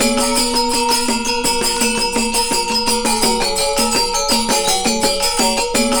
GAMELAN 3.wav